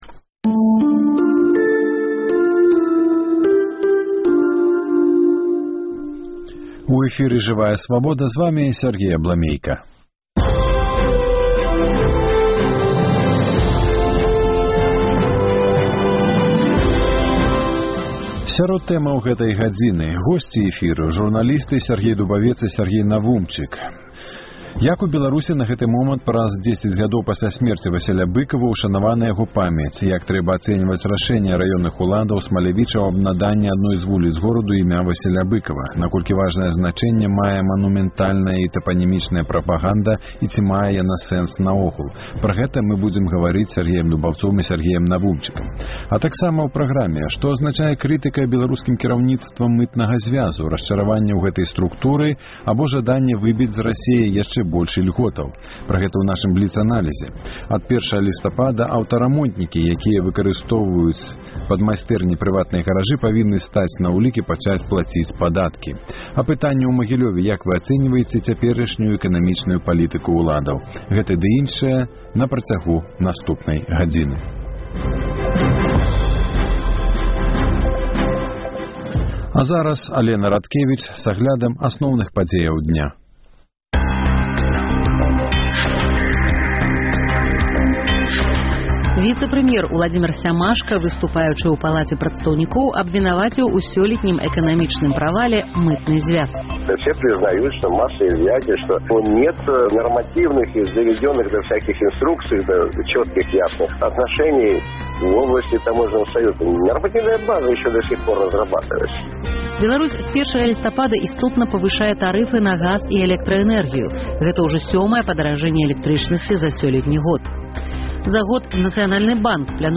Госьці эфіру – журналісты